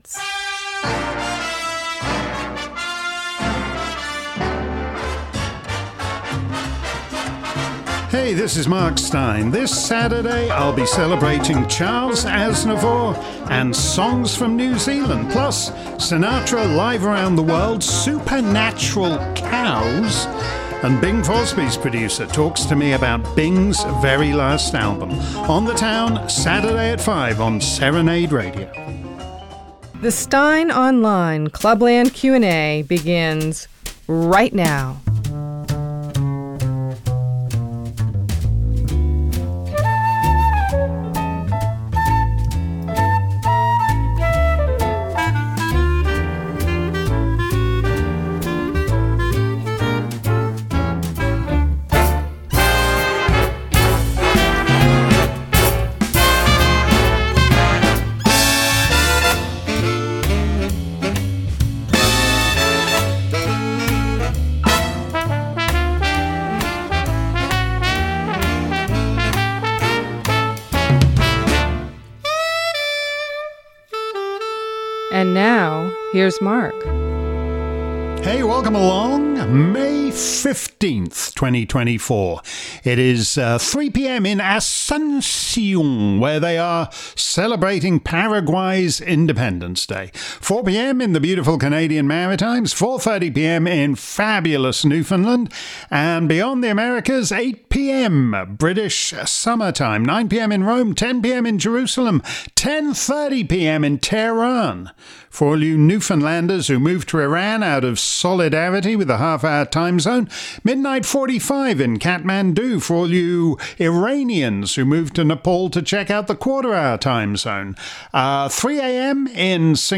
If you missed today's Clubland Q&A live around the planet, here's the action replay. Steyn was back at the microphone, fielding questions on many topics, from America's famously unique peaceful transfer of power to Ireland's sudden preference for a non-backstop, plus breaking news of the attempted assassination of the Slovak prime minister - and music for Paraguayan Independence Day.